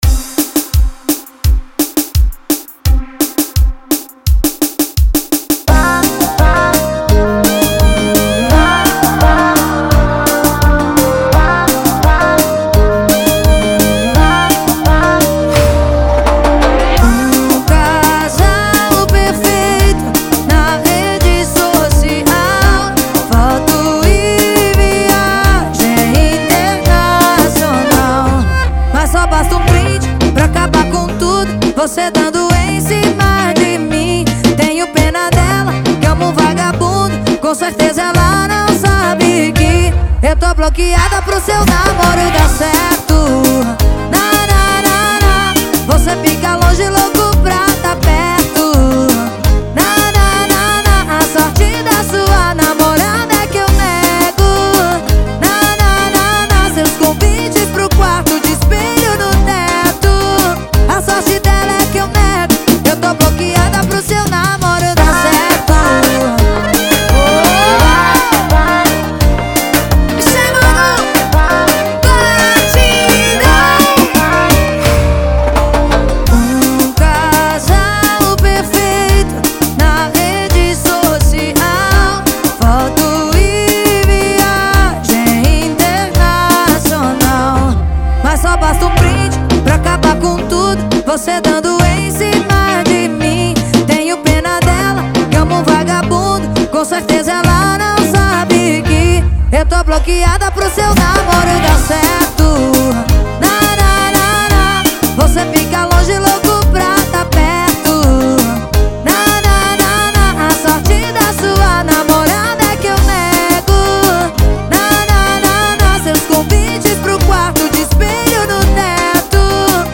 Arrocha, Tecnomelody, Eletrônica